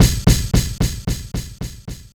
Track 15 - Snare Delay.wav